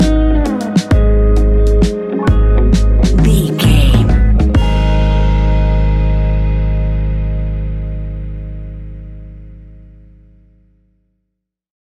Ionian/Major
G♭
chilled
laid back
Lounge
sparse
chilled electronica
ambient
atmospheric